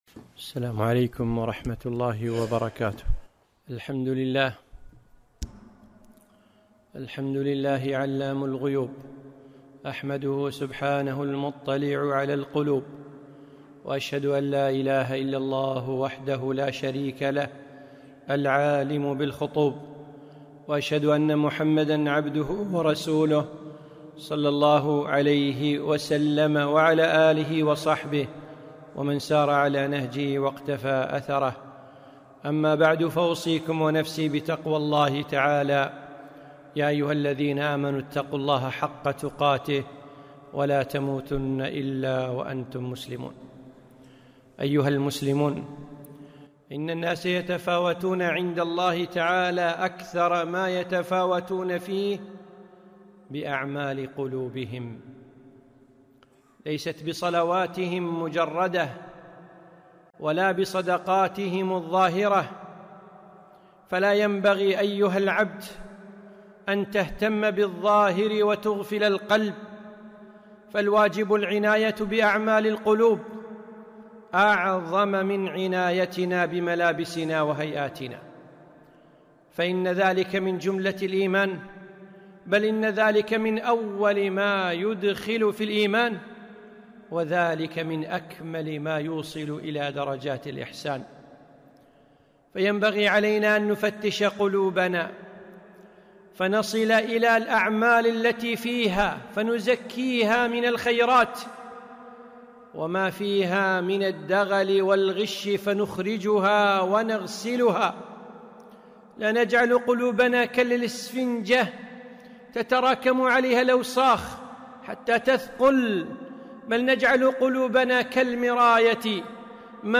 خطبة - أعمال القلوب